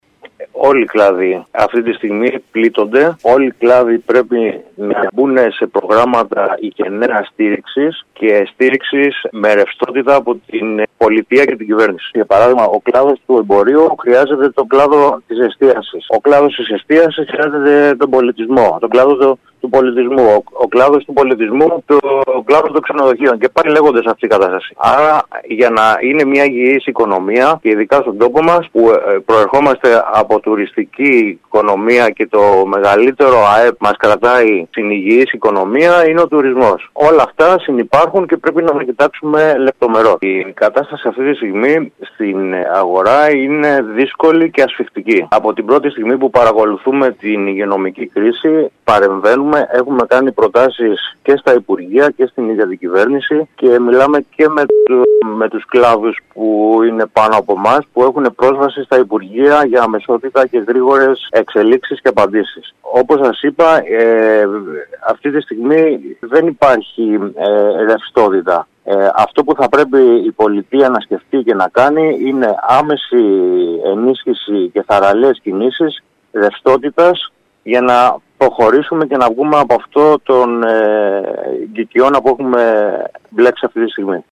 μίλησε σήμερα στην ΕΡΑ Κέρκυρας